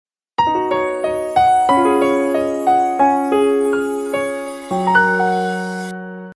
tree_bell.ogg